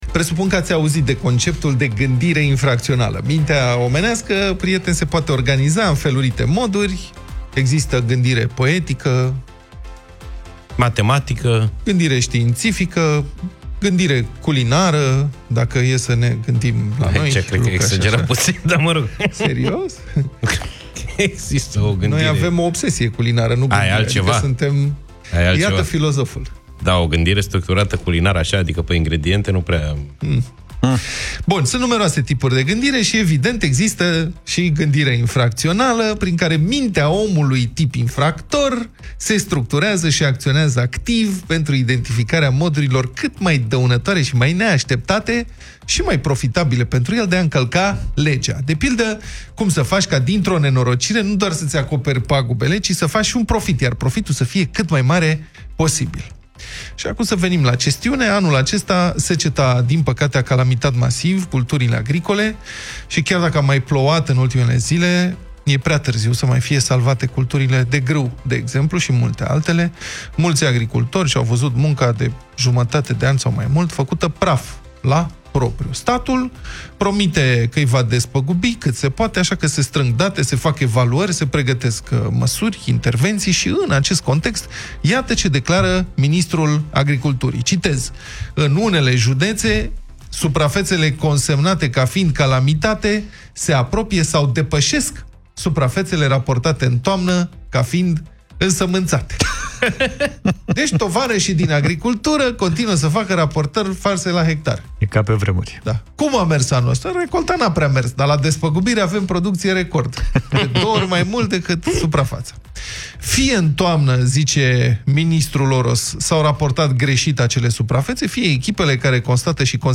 au vorbit despre acest subiect în Deșteptarea